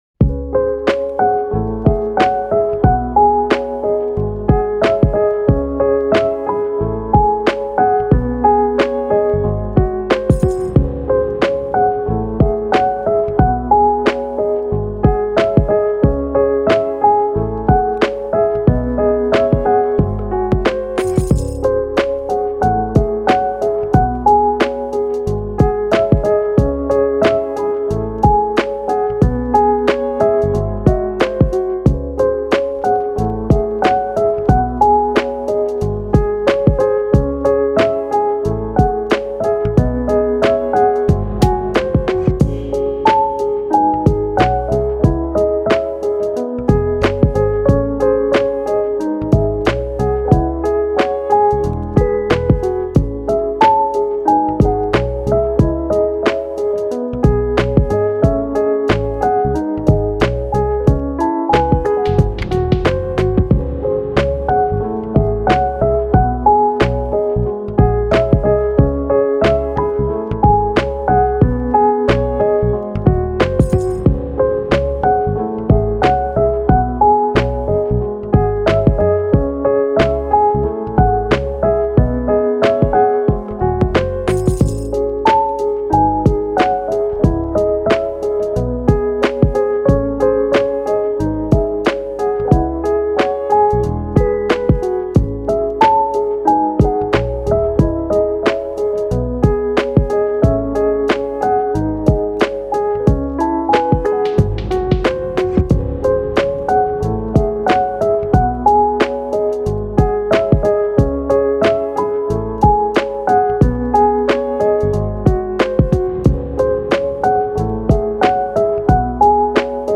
チル・穏やか
メロウ・切ない